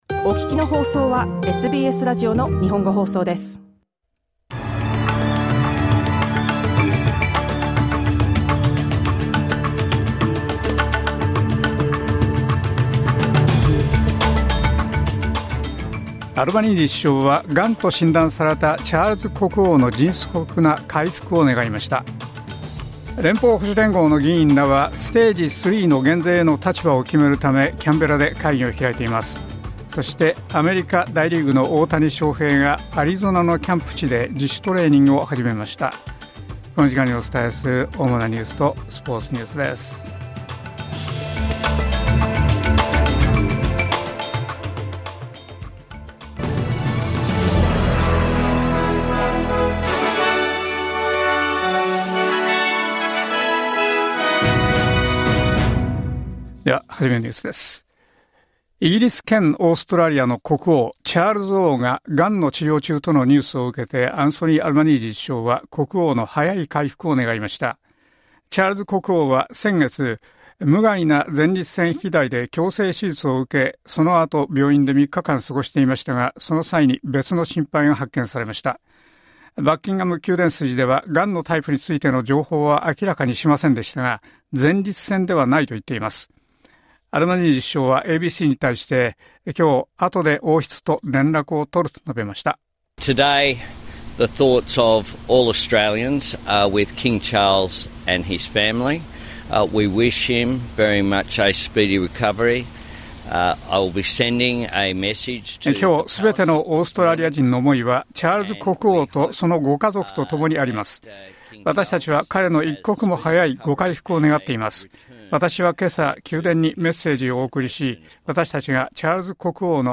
SBS日本語放送ニュース２月６日火曜日